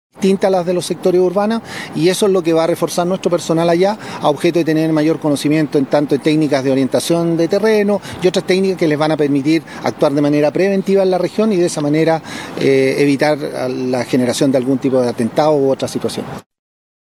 El general Eric Gajardo, jefe de la Zona de Orden Público e Intervención, explicó algunos de los objetivos que se buscan con esta retroalimentación. Detalló que aprenderán técnicas que se podrán aplicar de manera preventiva.